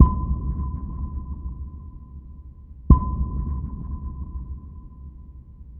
послушать звук эхолота),